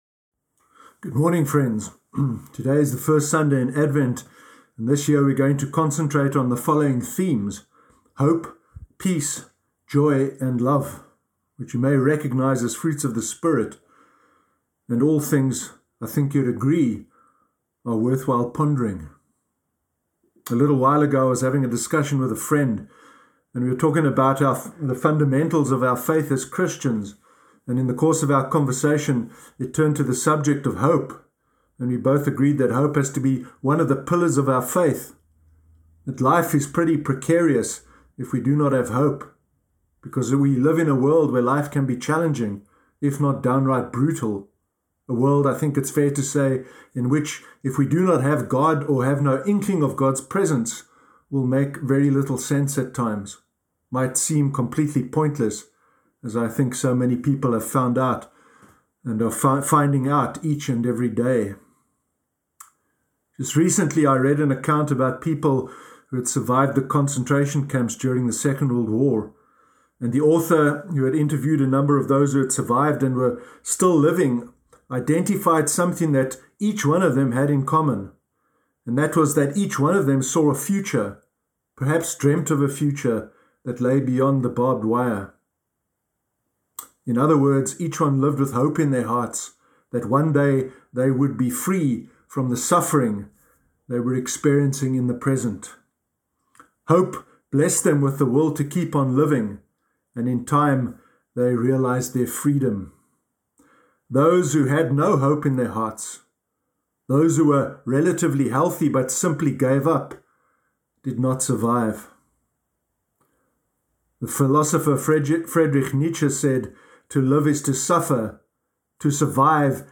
Sermon Sunday 29 November 2020